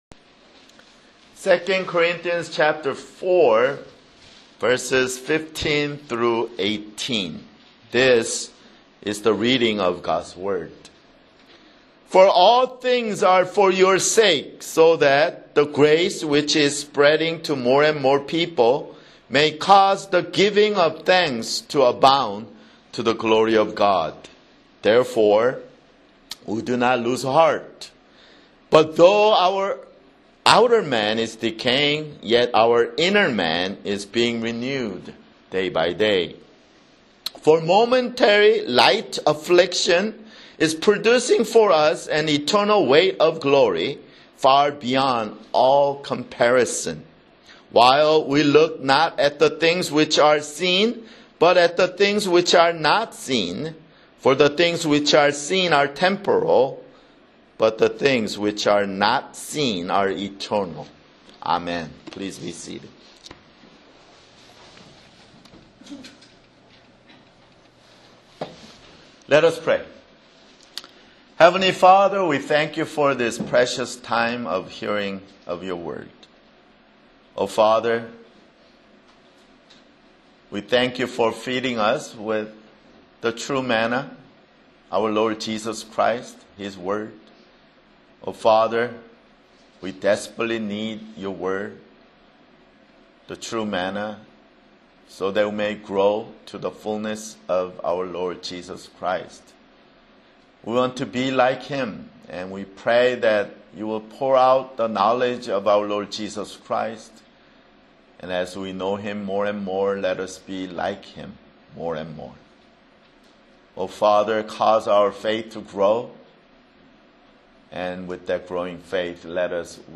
[Sermon] 2 Corinthians (24)